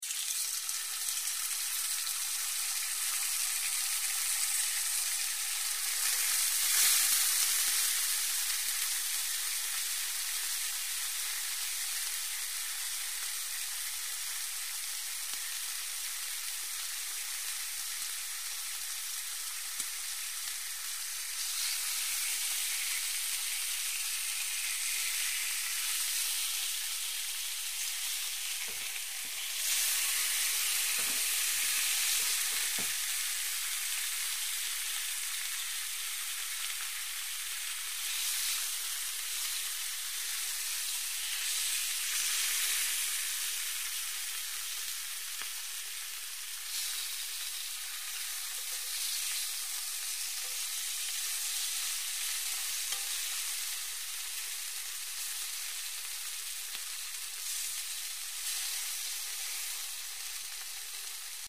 Звуки жарки, гриля
Жарят мясо на сковороде